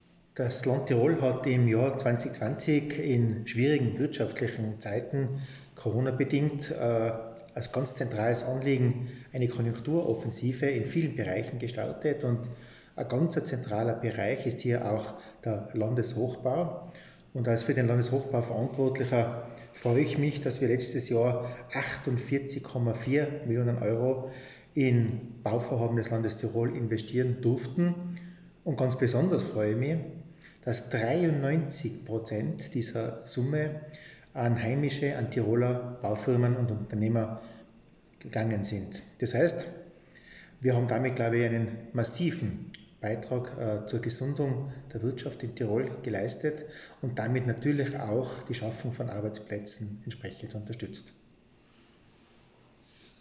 O-Ton_LR_Tratter_Hochbau_Bilanz_2020.mp3